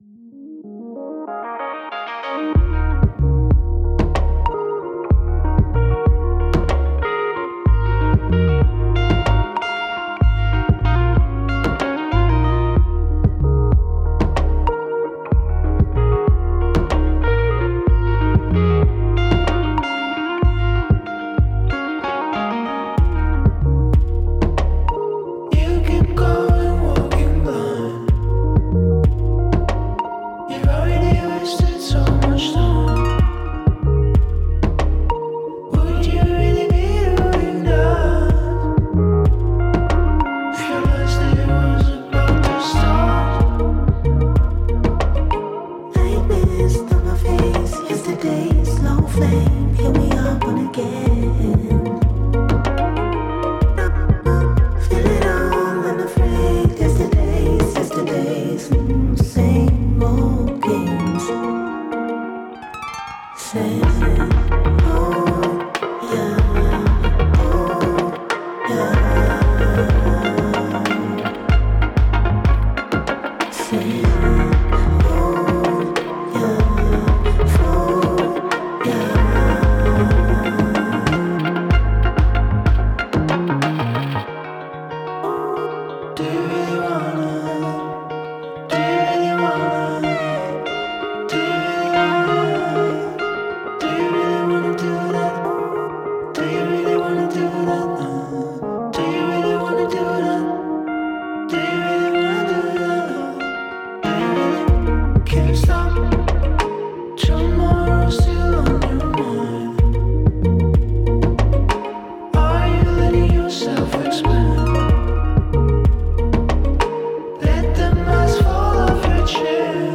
Breaks Dance Drum & Bass